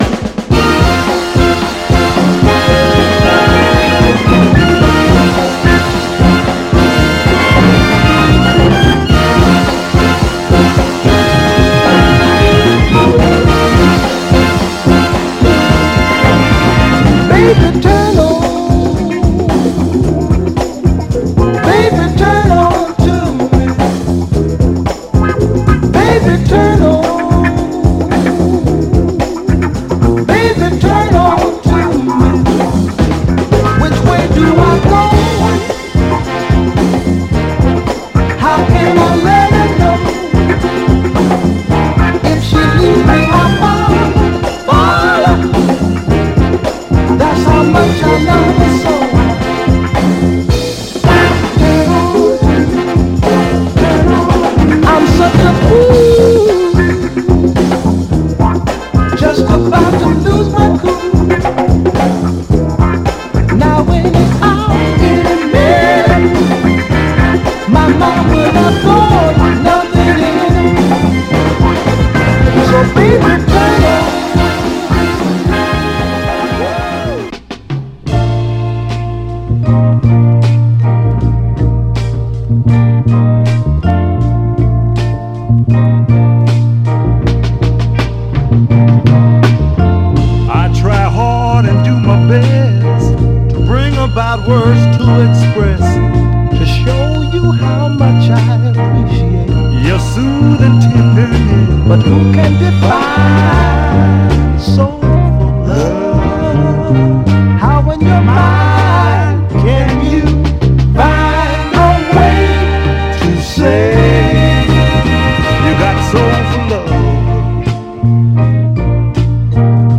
盤はごく薄いジュークボックス跡ありますが、グロスがありプレイ良好です。
※試聴音源は実際にお送りする商品から録音したものです※